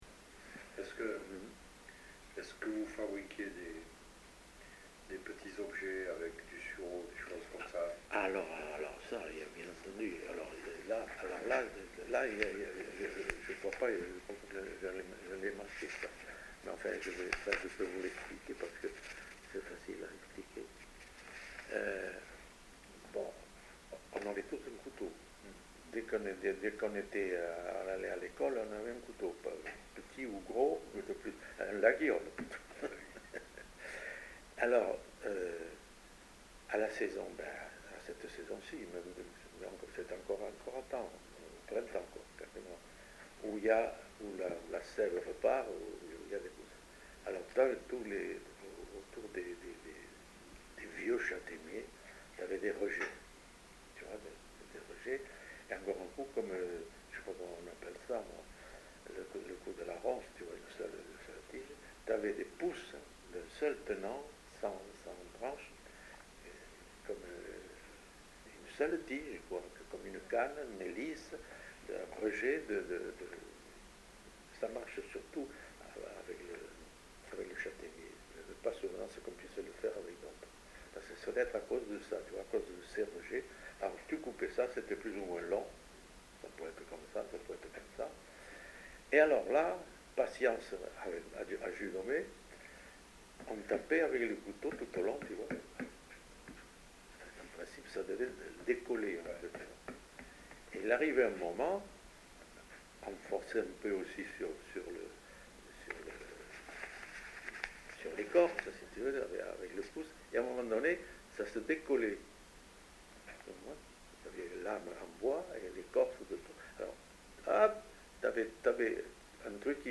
Lieu : Saint-Sauveur
Genre : témoignage thématique
Instrument de musique : sifflet végétal ; hautbois d'écorce